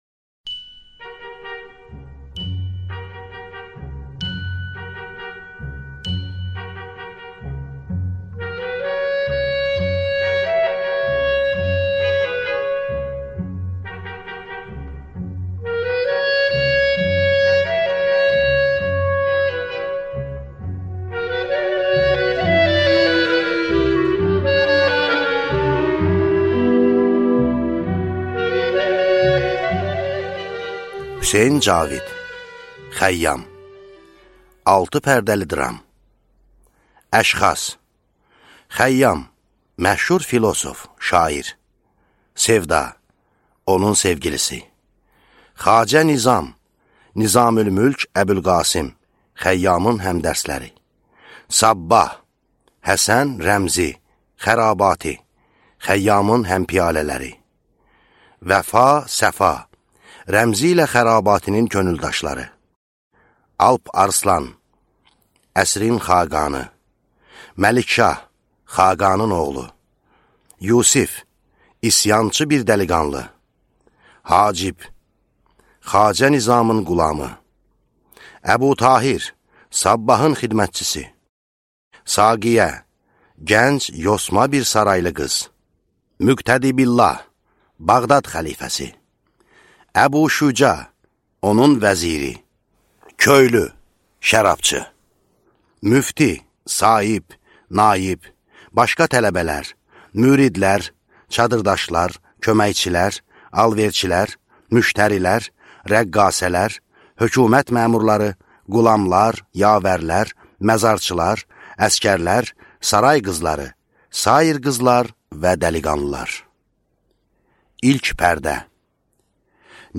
Аудиокнига Xəyyam | Библиотека аудиокниг